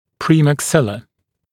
[ˌprɪmæk’sɪlə][ˌпримэк’силэ]резцовая кость, межчелюстная кость